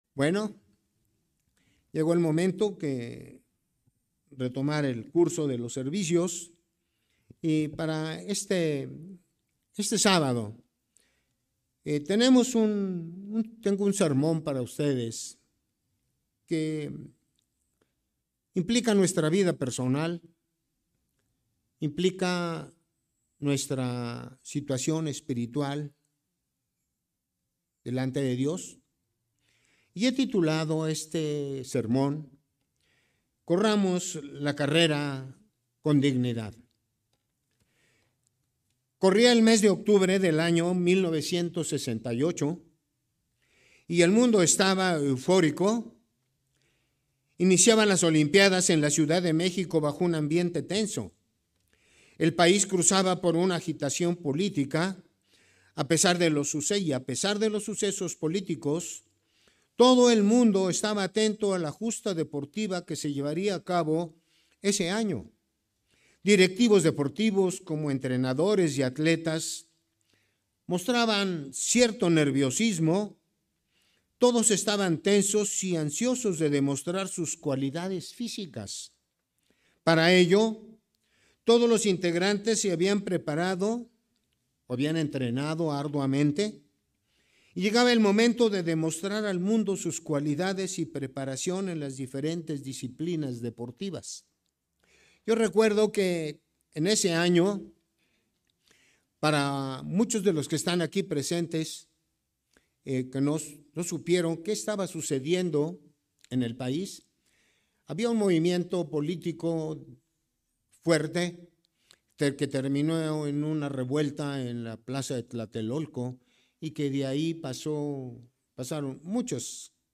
Sermones
Given in Ciudad de México